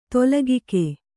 ♪ tolagike